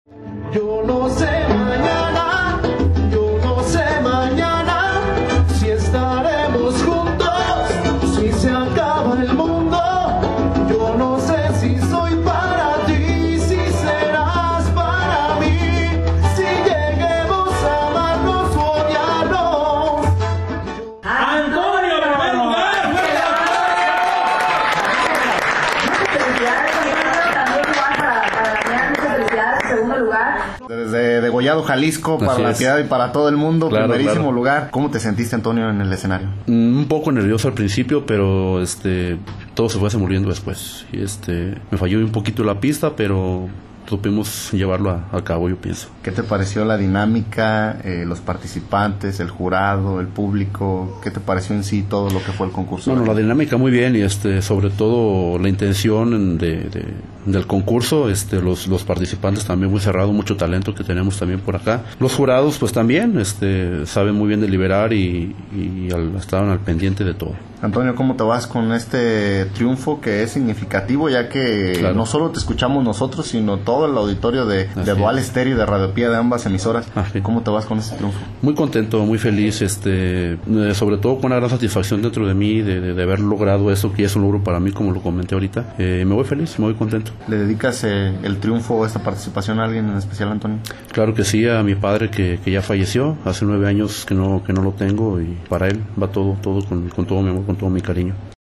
CONCURSO CON MOTIVO DEL DIA DEL PADRE EN LA RADIO